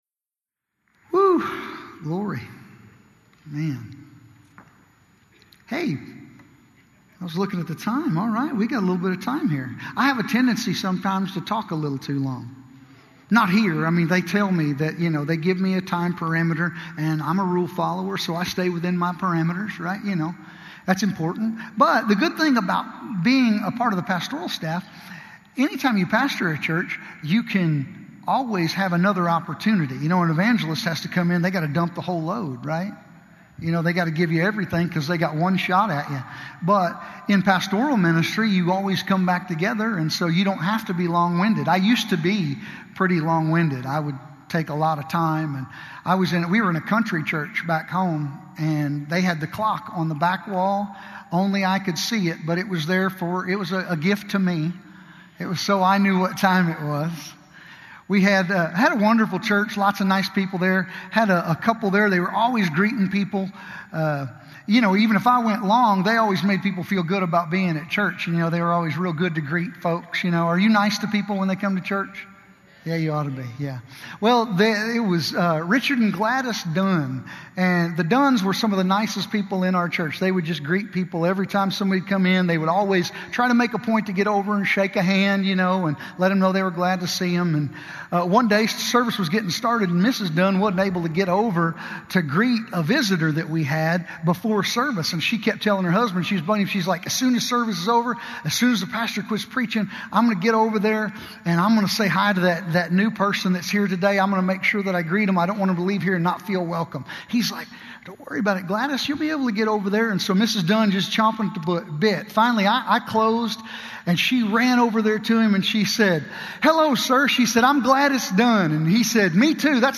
Podcasts for RHEMA Bible Church services held at the Broken Arrow, OK campus.